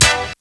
HORN HIT-L.wav